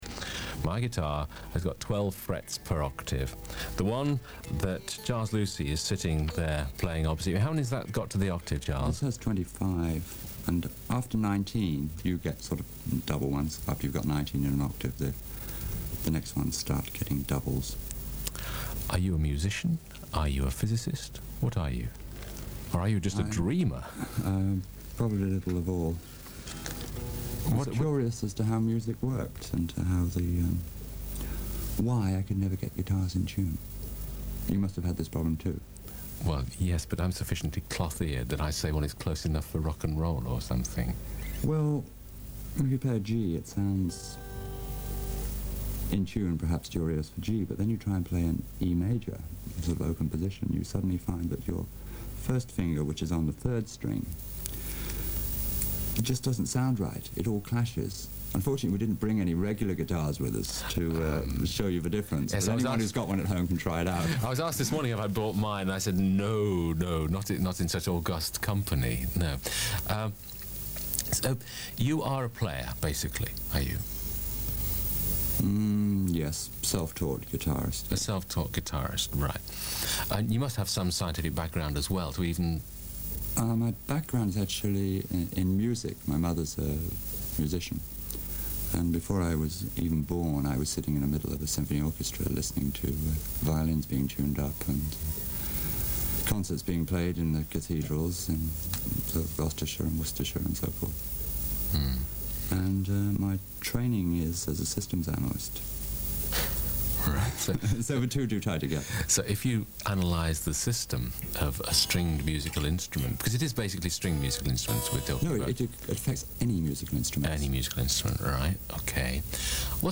BBC Oxford radio interview